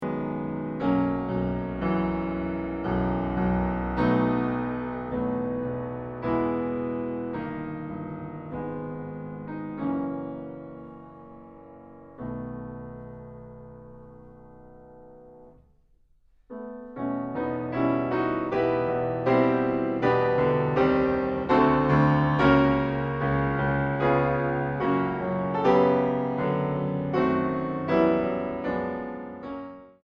Più lento 2.17